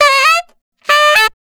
04-Saxual 1.wav